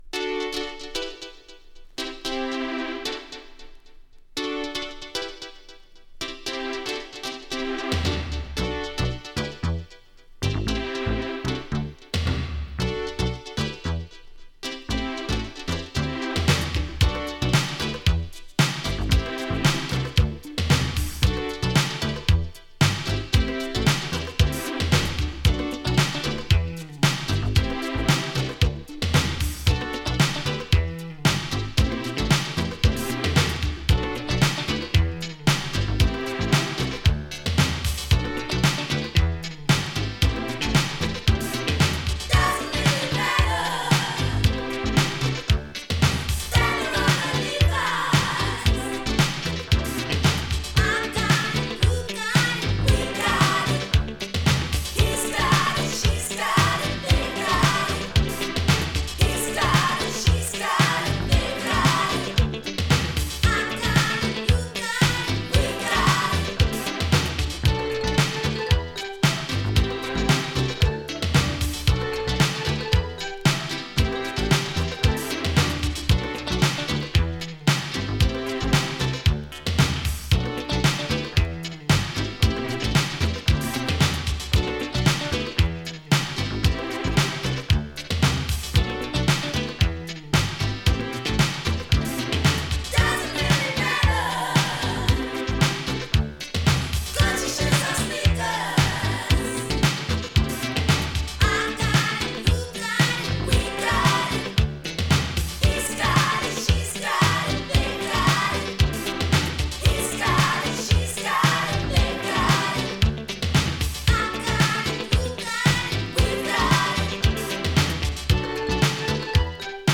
UK Electric Soul! イギリス出身の女性ソウルシンガー。